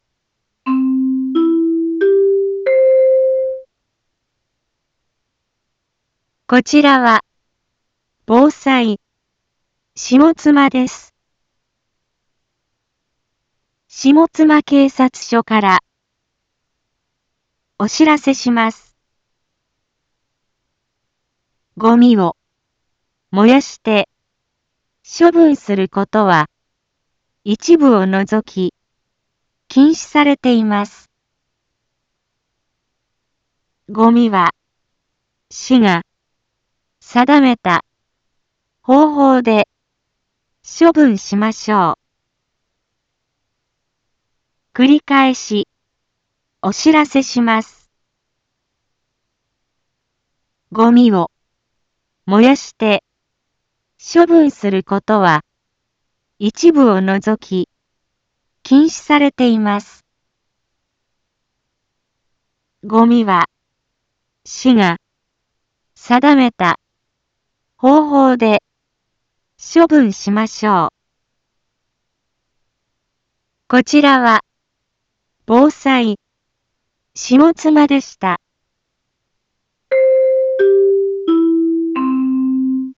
Back Home 一般放送情報 音声放送 再生 一般放送情報 登録日時：2024-03-25 10:01:27 タイトル：ごみの野焼き禁止（啓発放送） インフォメーション：こちらは、防災、下妻です。